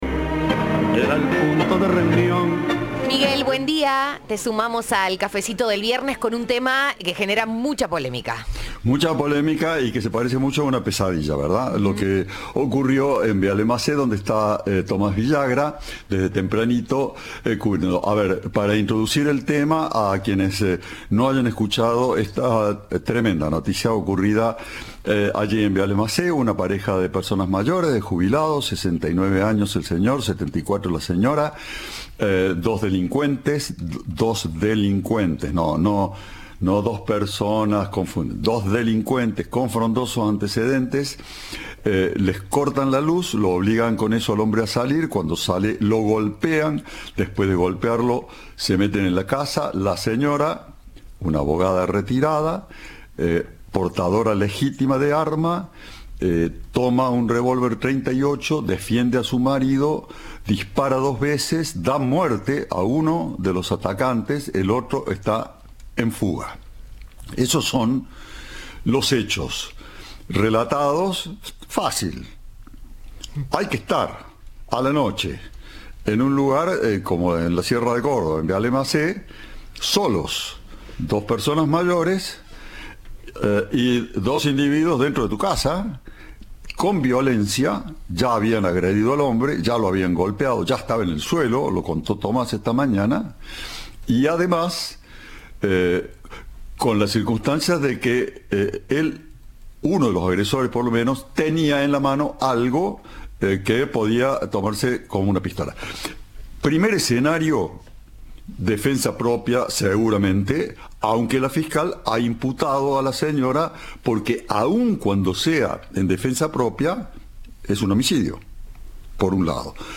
Cadena 3 habló con un vecino, quien señaló la creciente inseguridad. Además, el ex jefe de Policía de Córdoba, dijo que el hecho cumple con todos los elementos para que se configure la defensa.